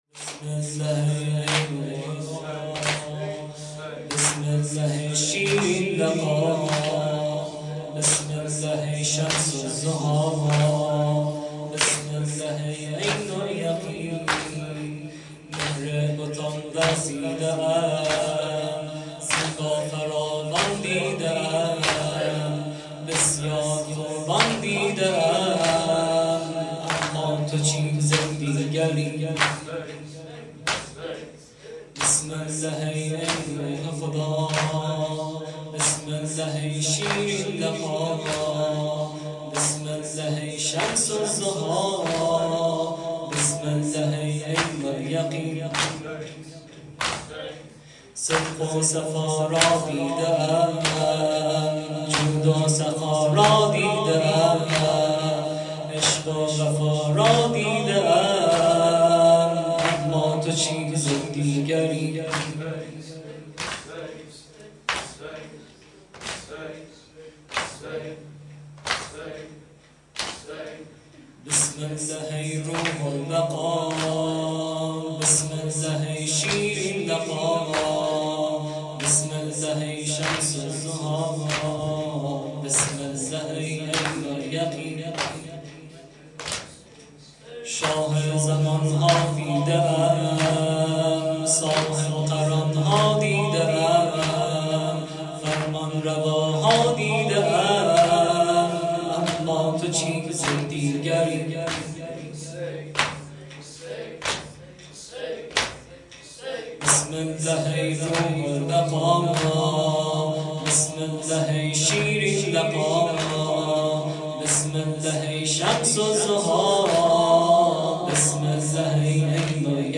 شب ۲۱ محرم 97